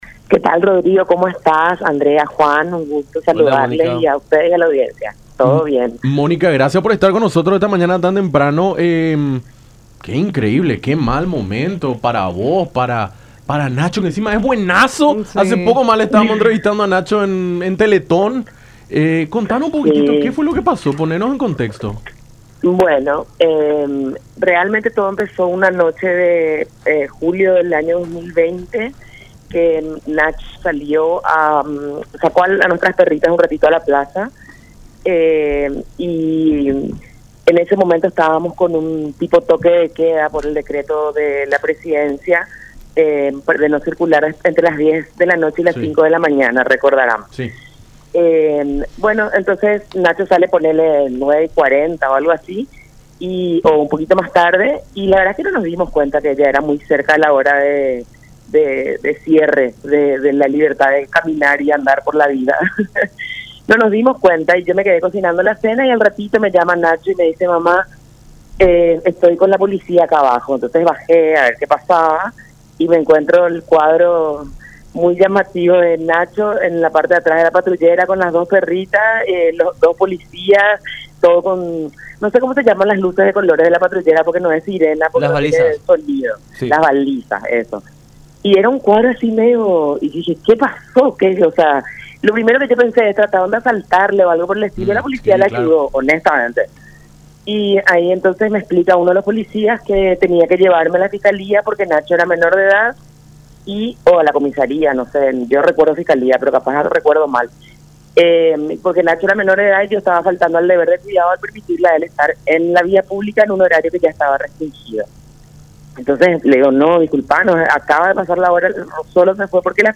en diálogo con Nuestra Mañana a través de La Unión.